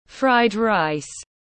Cơm rang tiếng anh gọi là fried rice, phiên âm tiếng anh đọc là /fraɪd raɪs/
Fried rice /fraɪd raɪs/